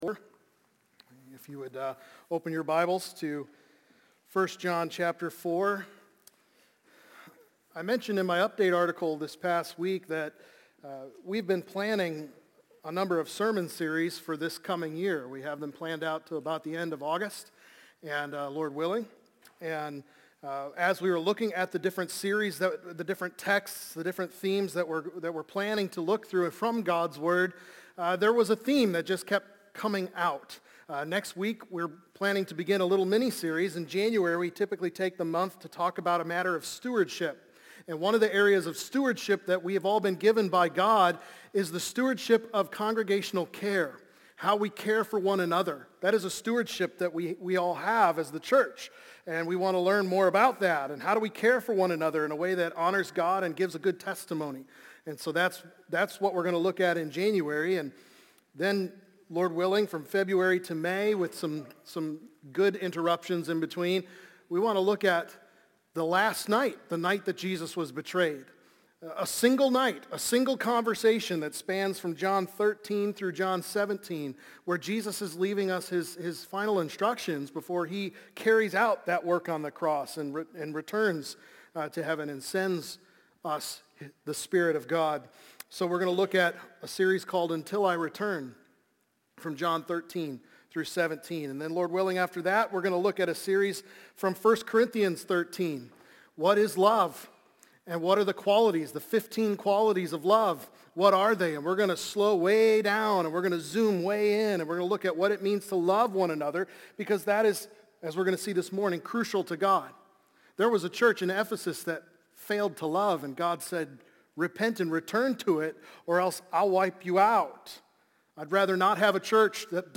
Let Us Know Love | Baptist Church in Jamestown, Ohio, dedicated to a spirit of unity, prayer, and spiritual growth